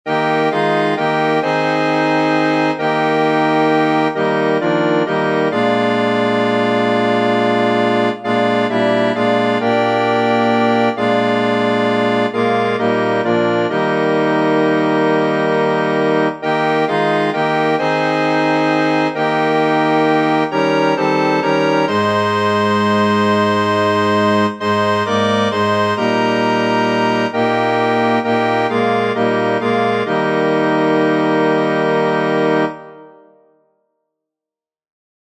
Flauta